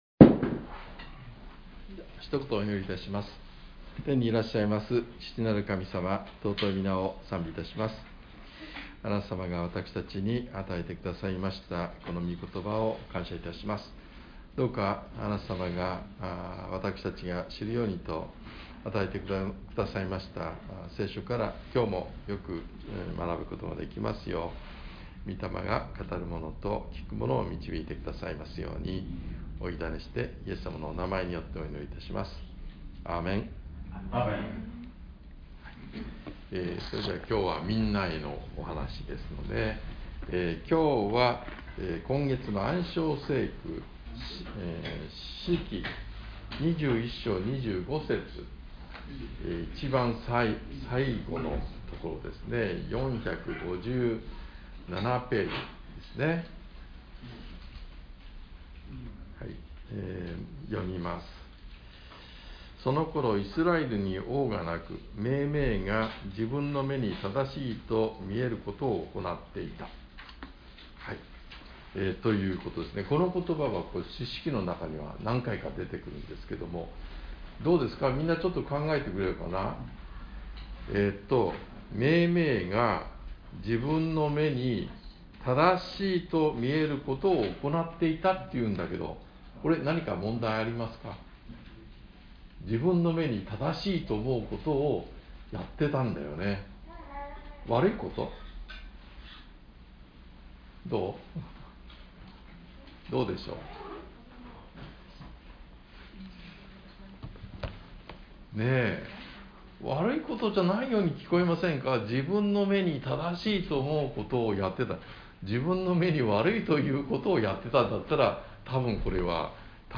ピラトによる裁判 2023年12月3日主日礼拝